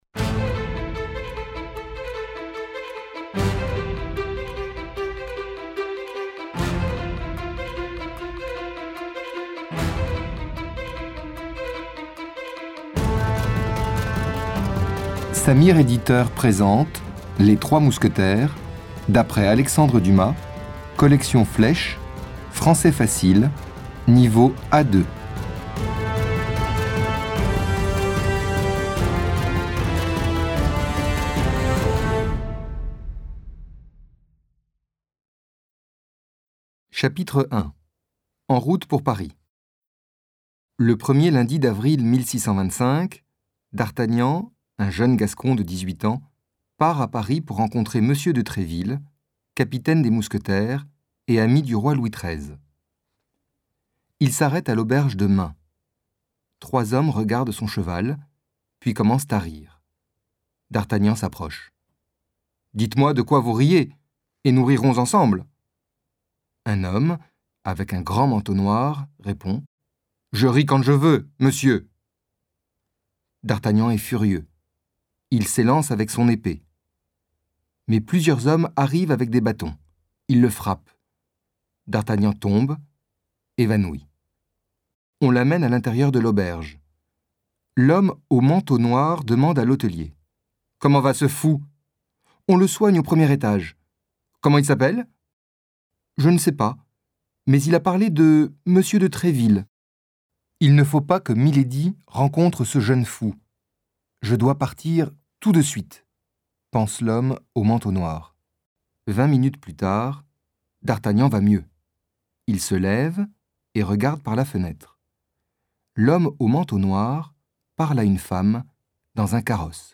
Livre audio - Les Trois Mousquetaires